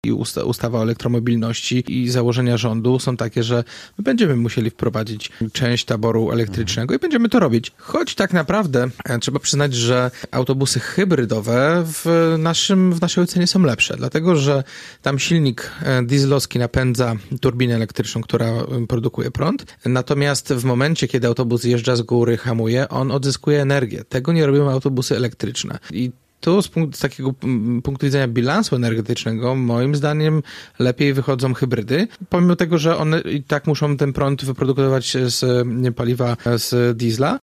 – Docelowo będziemy musieli wprowadzić takie wozy do naszego taboru- – mówi prezydent Jacek Wójcicki.